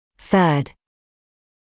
[θɜːd][сёːд]третий; треть, третья часть